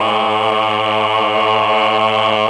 RED.CHOR1  3.wav